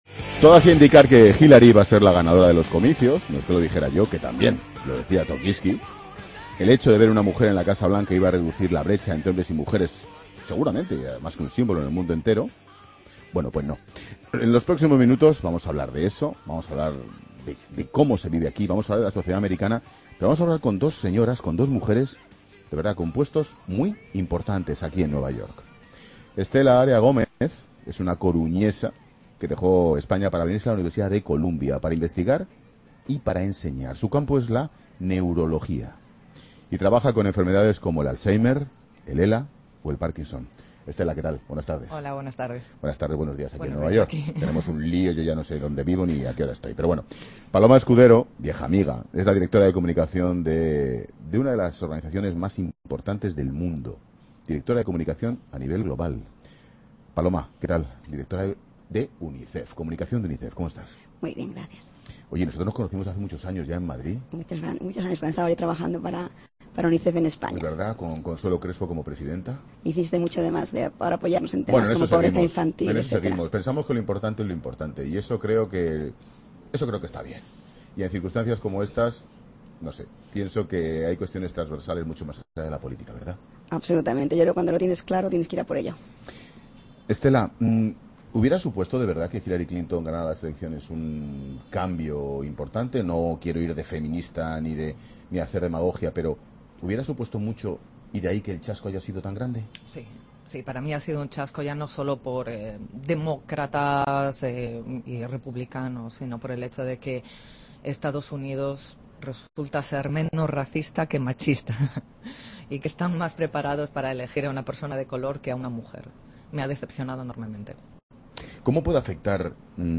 en 'La Tarde' desde Nueva York